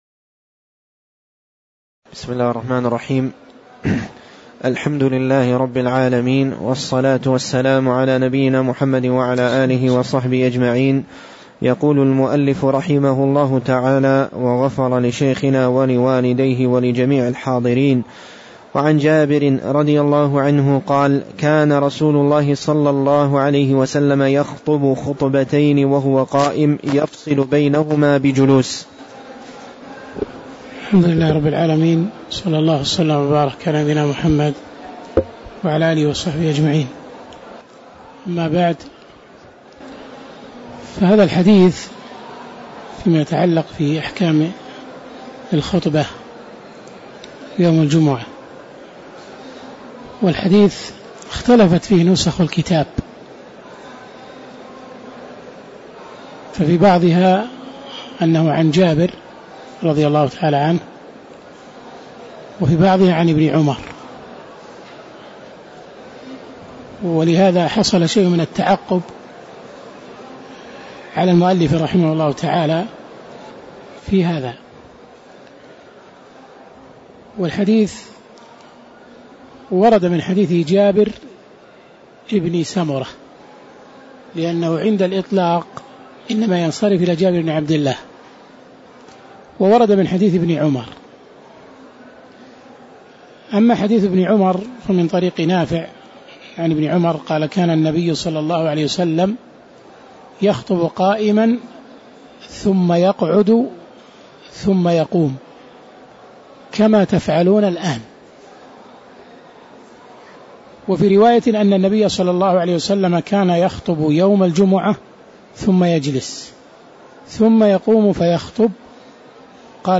تاريخ النشر ٢٦ جمادى الآخرة ١٤٣٧ هـ المكان: المسجد النبوي الشيخ